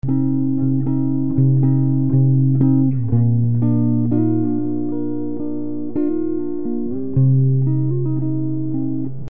Low frequencies passed, high frequencies attenuated
Low Pass